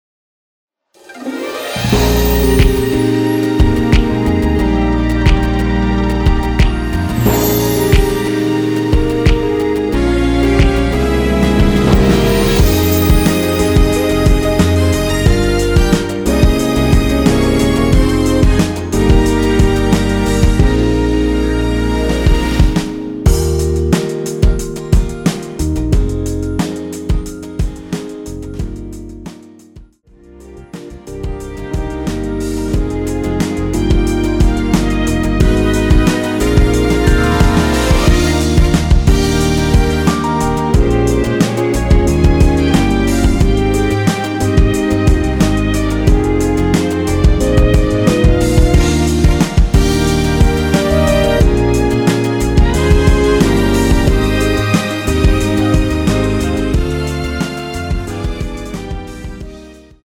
원키 (1절앞+후렴)으로 진행되는 MR입니다.
Eb
앞부분30초, 뒷부분30초씩 편집해서 올려 드리고 있습니다.
중간에 음이 끈어지고 다시 나오는 이유는